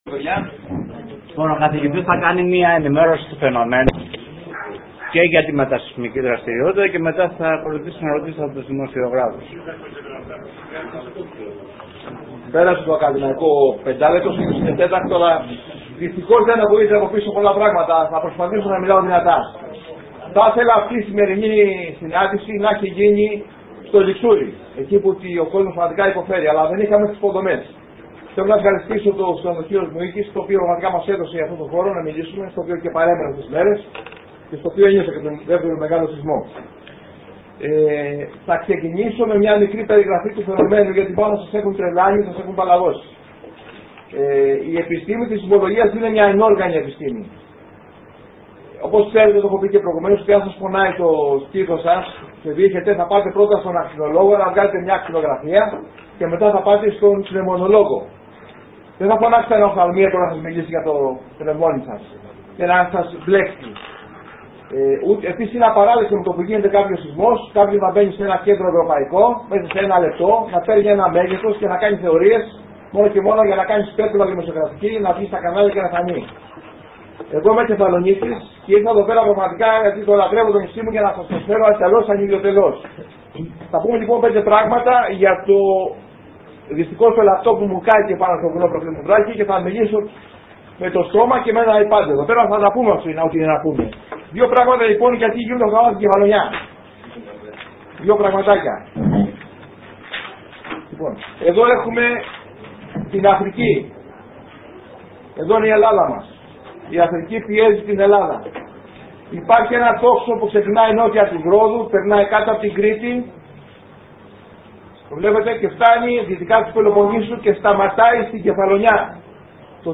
Αρκετός κόσμος είχε κατακλύσει την μικρή για μια τέτοια συνέντευξη, αίθουσα του ξενοδοχείου Μουίκη.